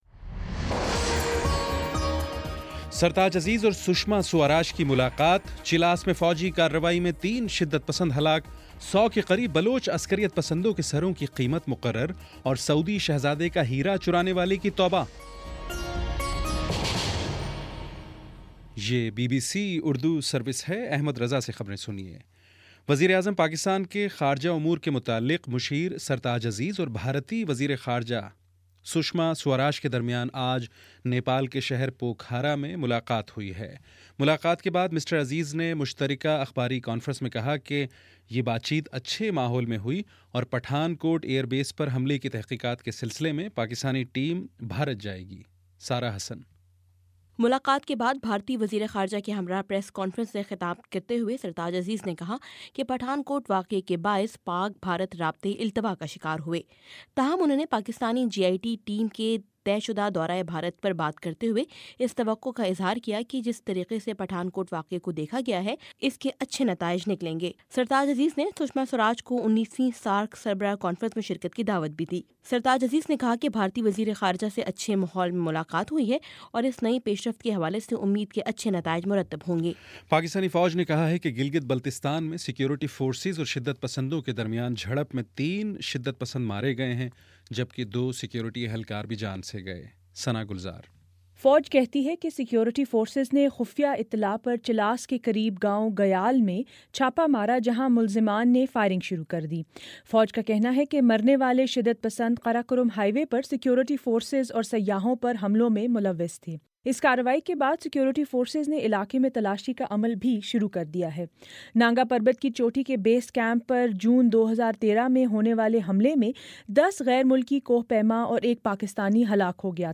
مارچ 17 : شام چھ بجے کا نیوز بُلیٹن